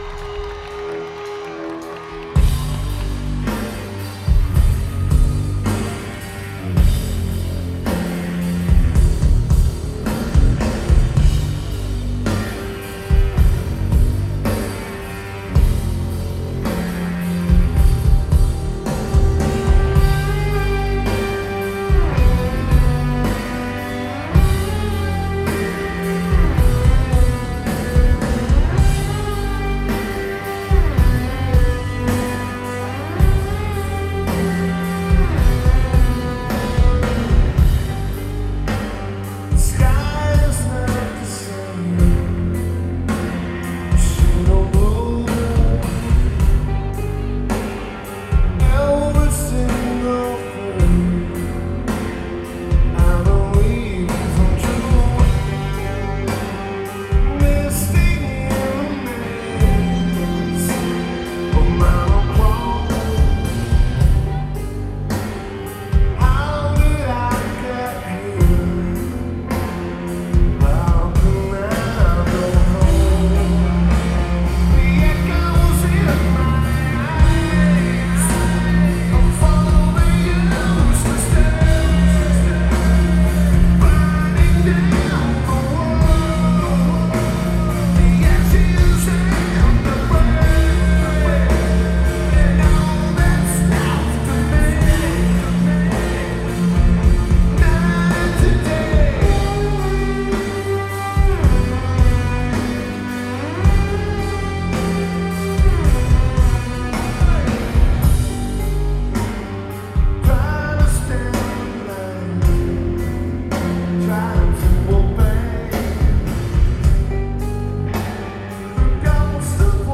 Shoreline Amphitheater